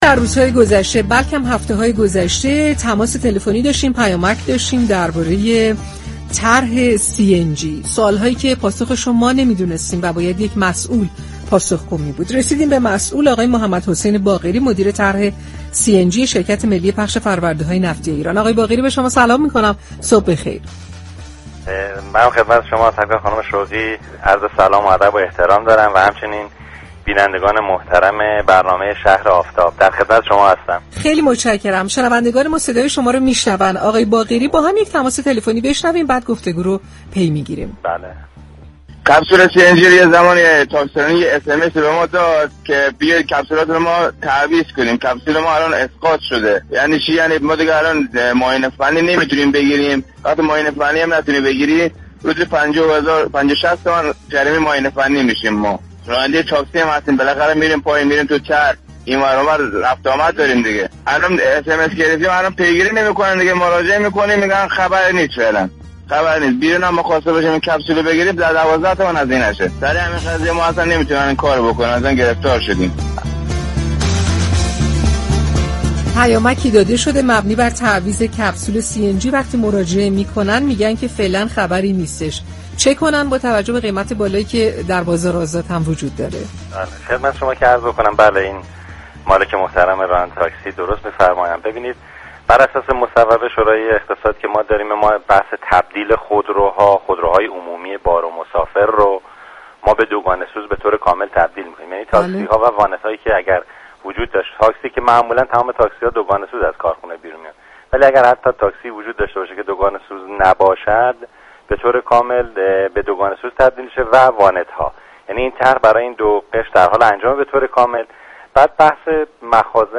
بخشی از برنامه «شهر آفتاب» به بررسی مشكلات و مساپل مطرح شده از سوی شنوندگان برنامه اختصاص دارد. در برنامه امروز یك راننده تاكسی از بی‌توجهی شركت تاكسیرانی درخصوص جایگزینی مخزل سوخت سی‌ان‌جی گلایه داشتند و عنوان كردند كه به دلیل نداشتن مخزن سی‌ان‌جی نمی‌توانند معاینه فنی بگیرند در نتیجه جریمه می‌شوند.